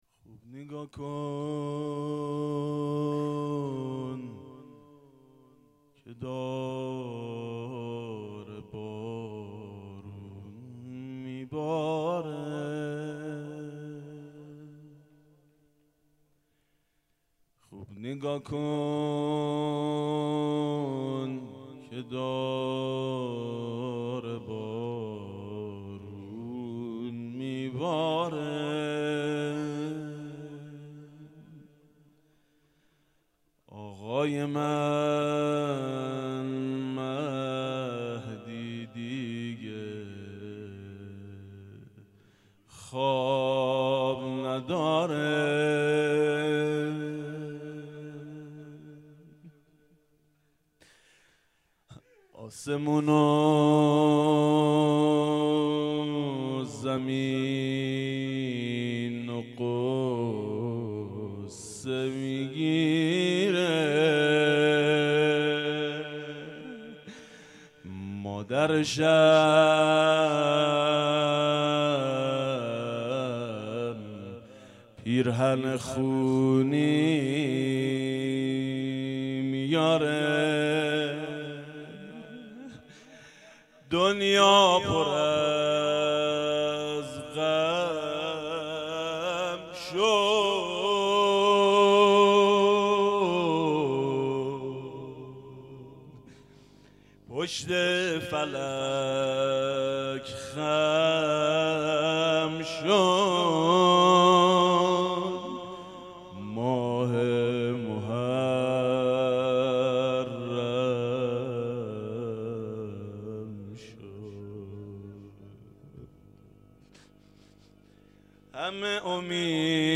حسینیه بنی فاطمه(س)بیت الشهدا
0 0 روضه شب اول محرم 97